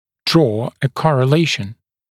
[drɔː ə ˌkɔrə’leɪʃn][дро: э ˌкорэ’лэйшн]вывести корреляцию, вывести зависимость